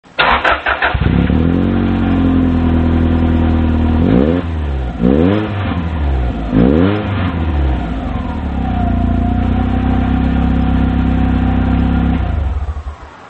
音(その2・後部での空ぶかし音　mp3形式・65kB）
でもサイレンサーはストレート構造なのでやっぱり音は結構耳について響きます。